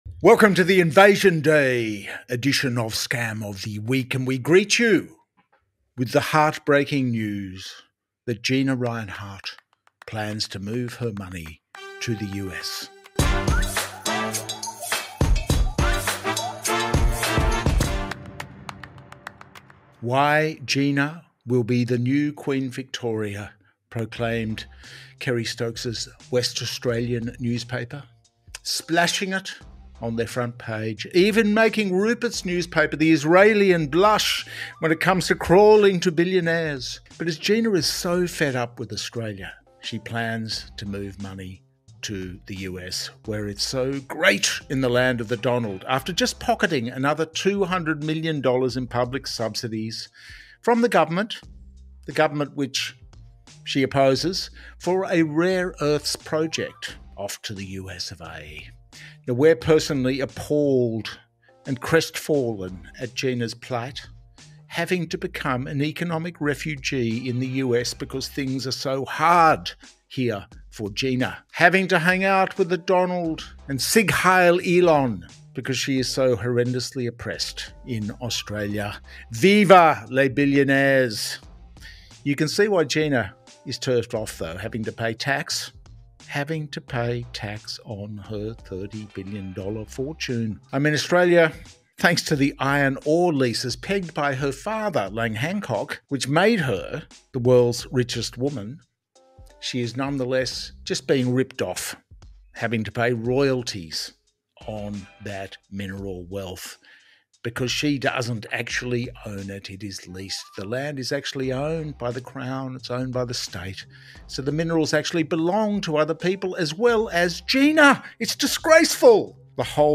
It also touches on controversies surrounding climate change, Indigenous representation, and cultural debates about Australia Day, offering a tongue-in-cheek critique of the political and economic landscape. The tone is sarcastic, aiming to provoke thought about systemic inequalities and political hypocrisies.